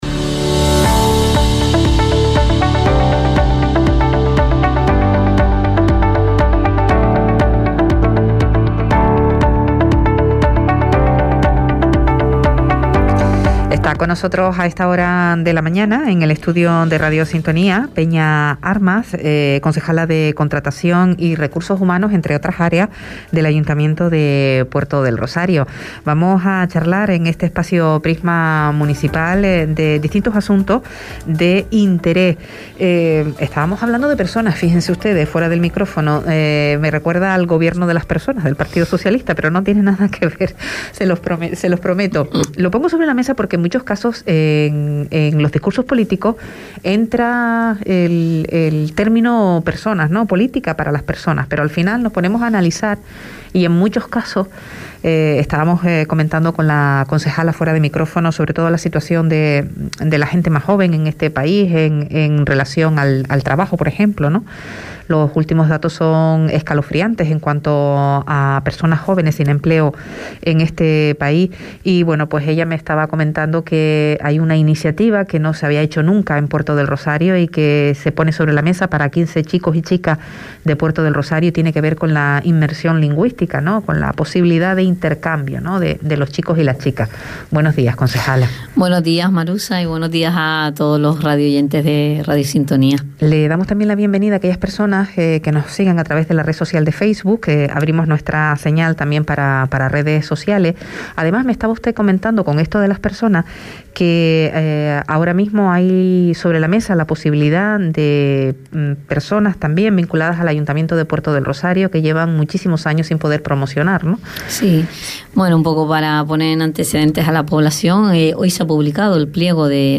Prisma Municipal | Entrevista a Peña Armas, concejala del Ayuntamiento de Puerto Del Rosario – 27.04.22